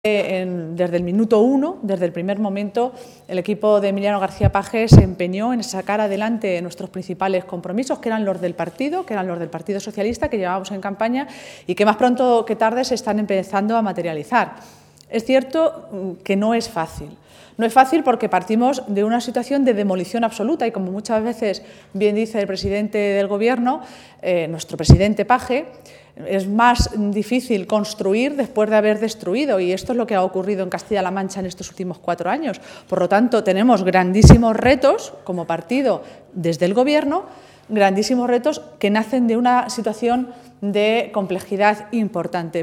Maestre ha realizado estas declaraciones en un receso de la reunión del Comité regional socialista, el primero que se celebra tras las elecciones autonómicas y municipales del pasado mes de mayo.